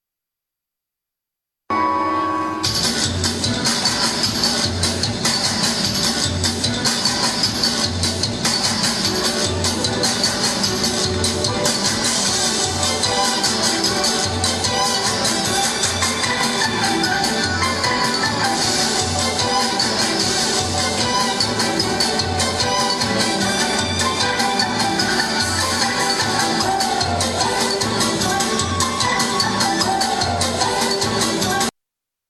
Кусочек концертного выступления на опознание.
Russian Folk.mp3